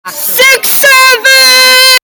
The “67” sound effect plays the popular meme audio. It delivers a funny, unexpected tone perfect for TikTok, YouTube Shorts, Reels, videos, and clips.
Genres: Sound Effects